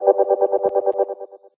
whoosh_longer_chopper_6.ogg